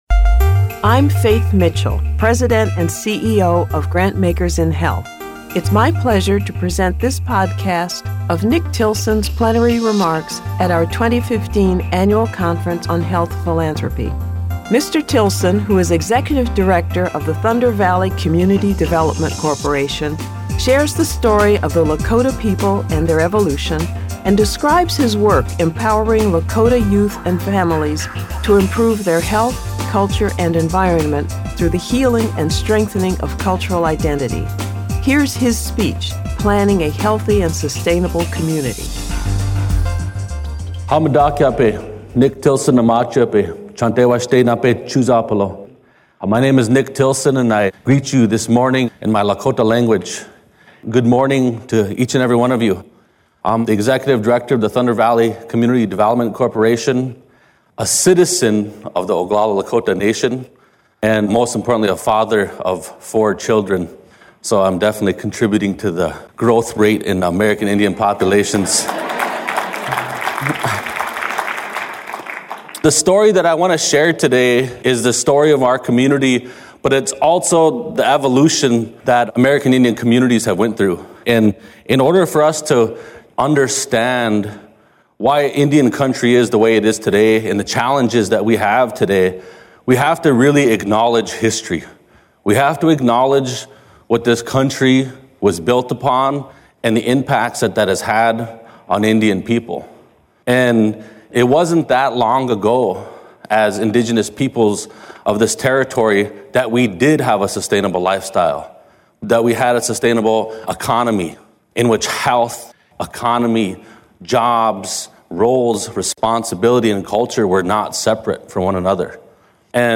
2015 Annual Conference Plenary Remarks